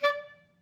DCClar_stac_D4_v2_rr2_sum.wav